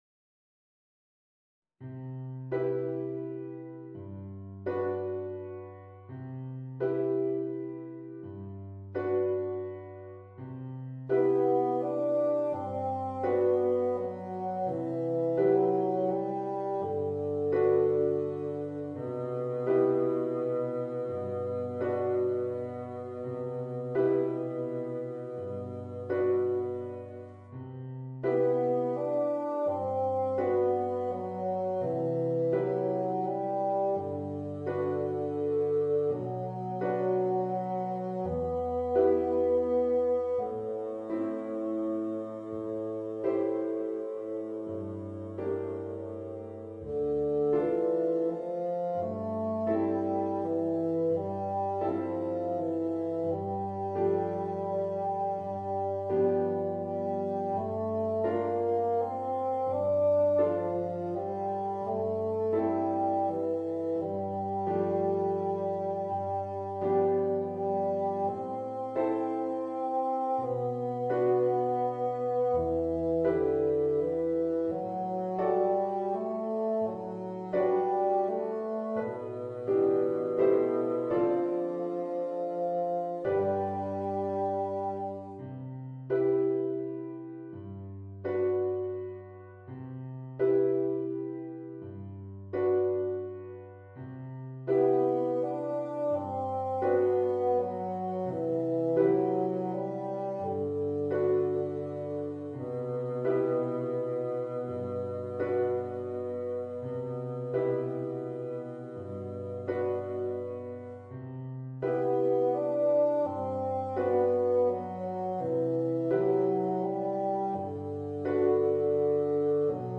Voicing: Bassoon and Organ